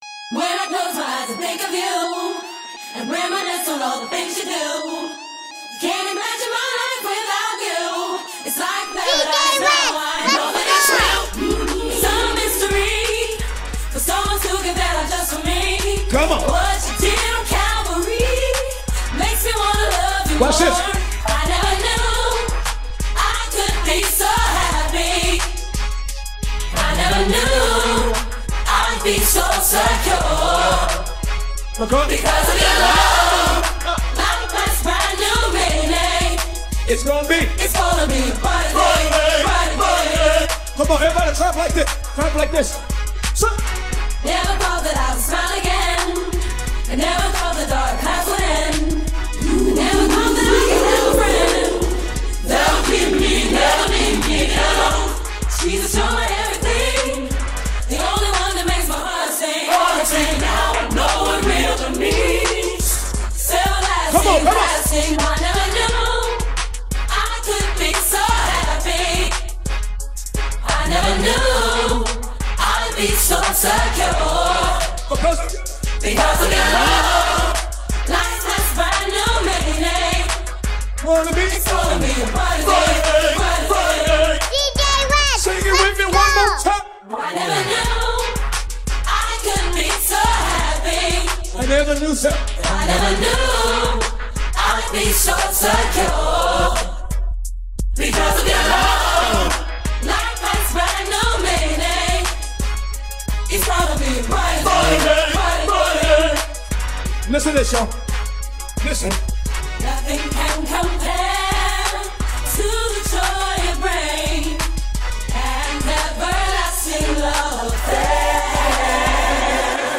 Gospel
HipHop